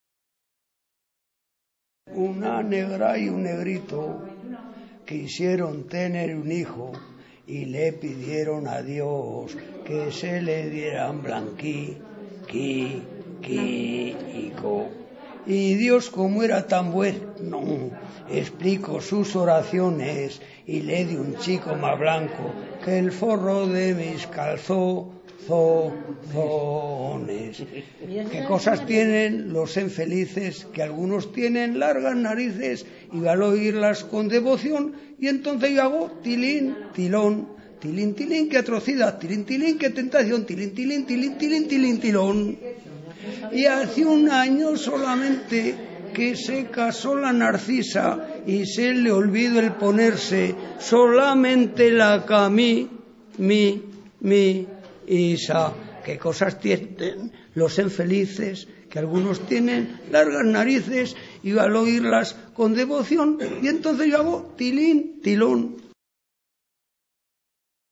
Cuplé "Que cosas tienen los enfelices" (1.05)
Observaciones: Los cuplés por contener muchas veces letras de contenido "picante" y fácilmente representable a modo de escena burlesca, eran normalmente acogidos como cantos de juerga y borrachera por los mozos.